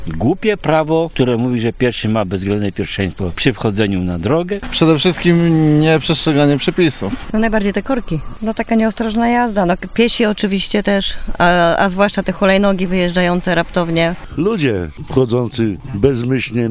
Zapytaliśmy kierowców ze Stargardu co ich najbardziej denerwuje na drogach.